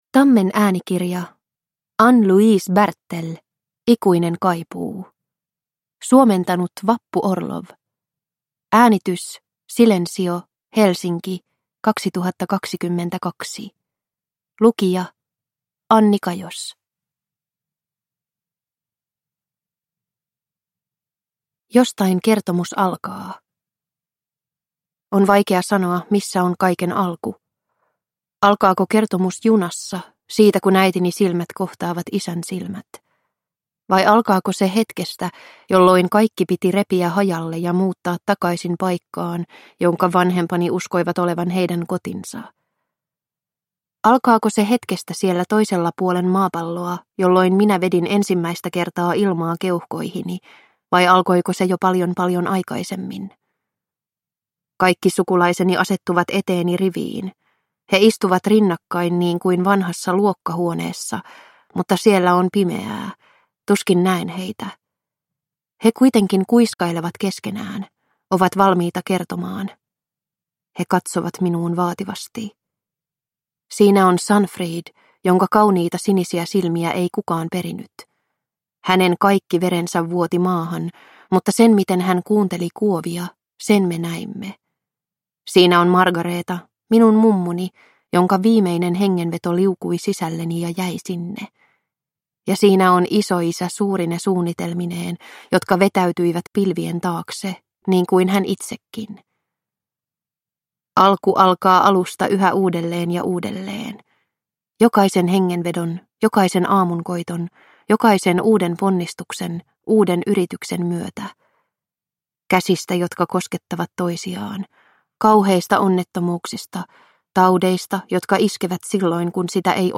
Ikuinen kaipuu – Ljudbok – Laddas ner